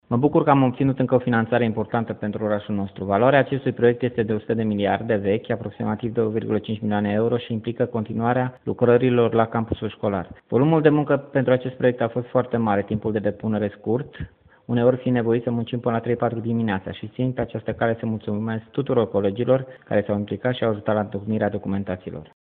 Primarul oraşului Dumitru Ursu a declarat, în exclusivitate pentru Radio România Reşiţa, că sumele atrase sunt de aproximativ 2 milioane şi jumătate de euro.
În cadrul proiectului se va construi o sală de sporţ o şcoală generală de 10 clase, un cămin pentru elevi şi mai multe apartamente cu o cameră pentru cadrele didactice, dar şi o centrală termică ce va alimenta toate aceste construcţii, a mai precizat primarul din Oravița, Dumitru Ursu.